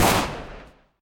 sounds_pistol_fire_02.ogg